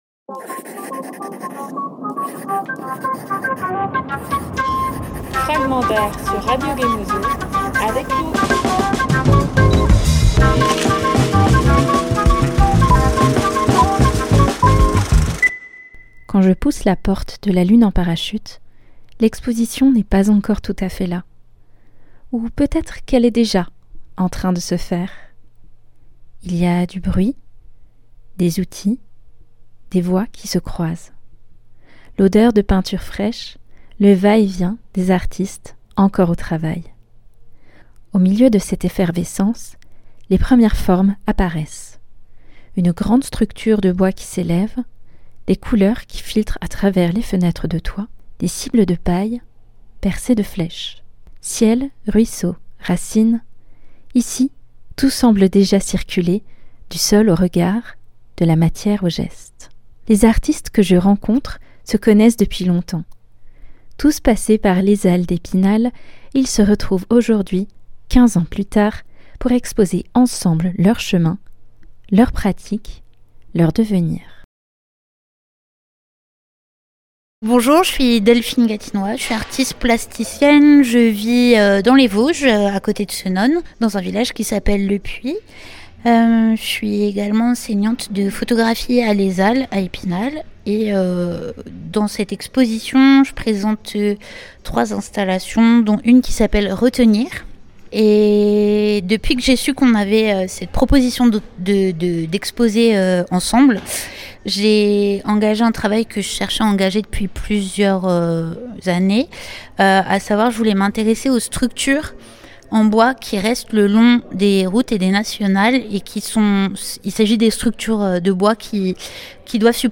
À La Plomberie à Épinal, l’exposition Ciel, ruisseau, racines se construit encore, dans le bruit des outils et l’odeur de la peinture fraîche.